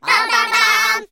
Звук оповещения о новом подписчике веселье